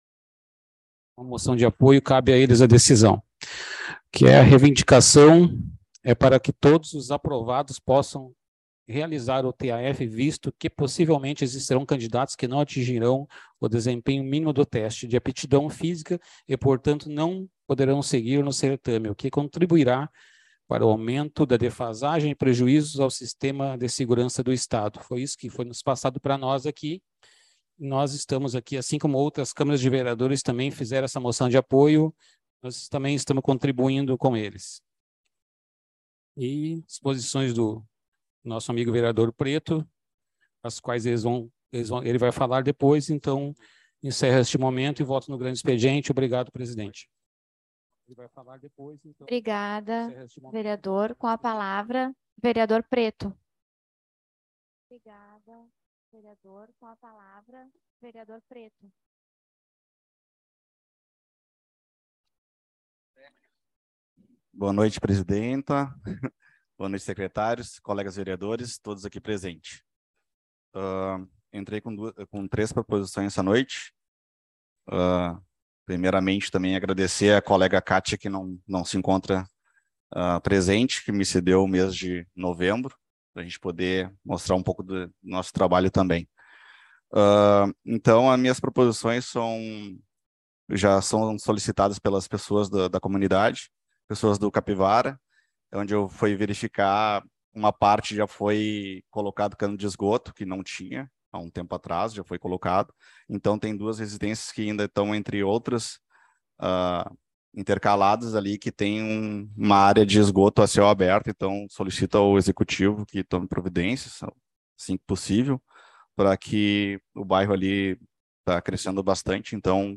Sessão Ordinária